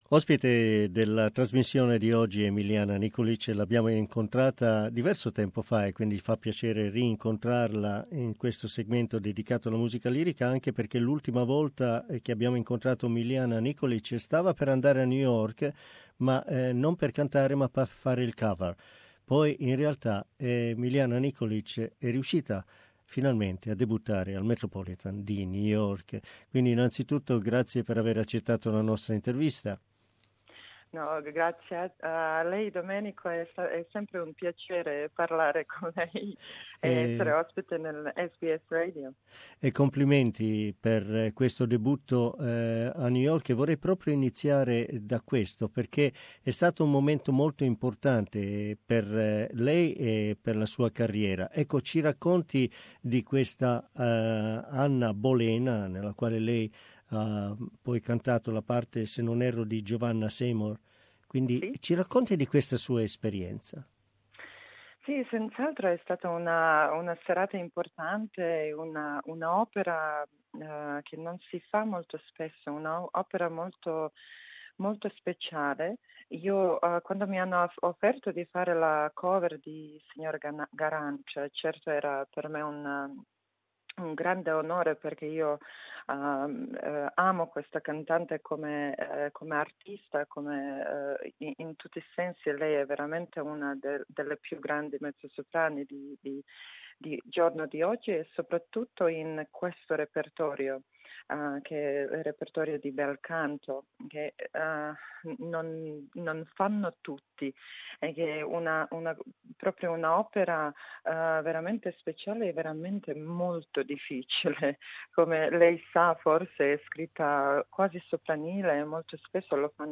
Many famous singers conclude their careers without having had the opportunity to perform in what is today considered the most prestigious theatre in the world. Our interview.